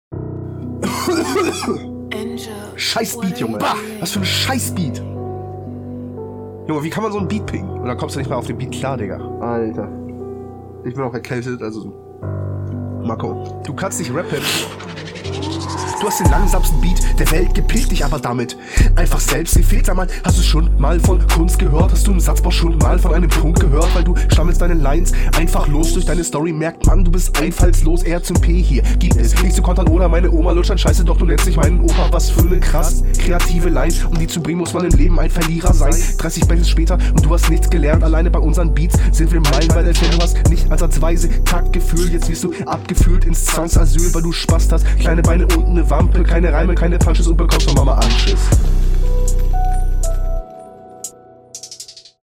Flowlich halt besser, da lockerer und paar Variationen.